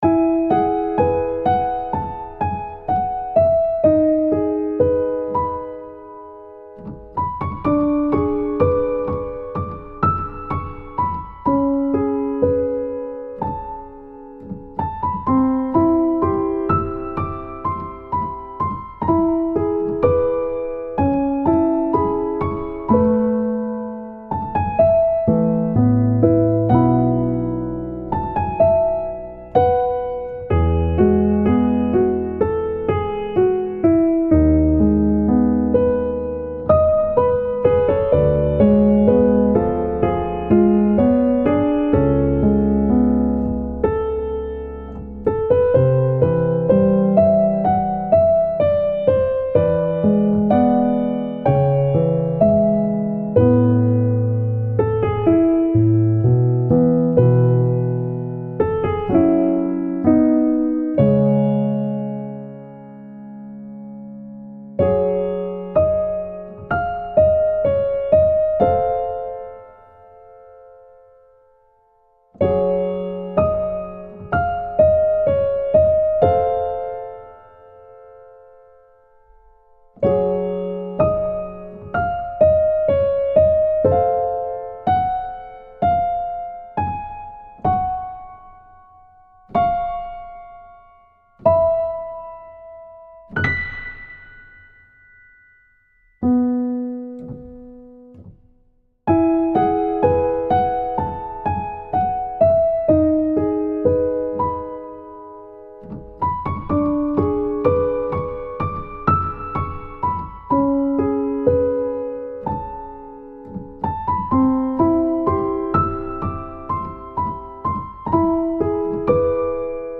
優しさと切なさが溢れているピアノ曲です。